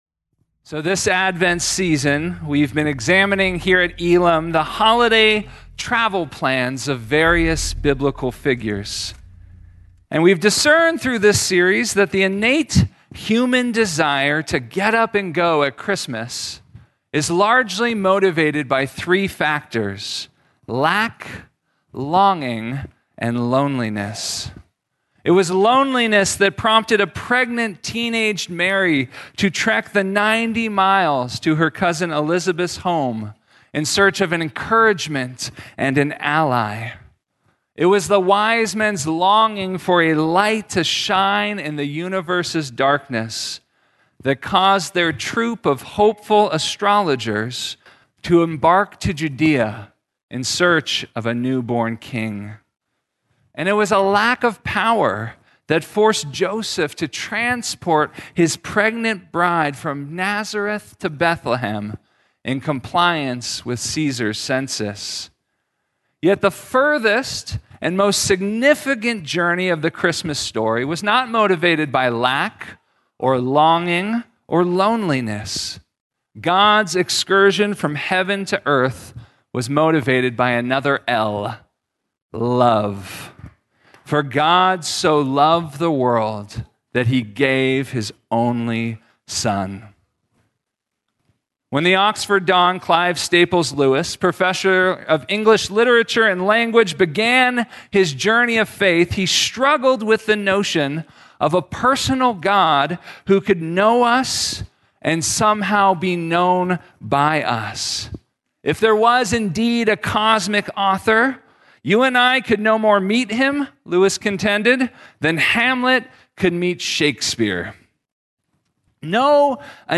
Christmas Eve Candlelight Service – God Visits Earth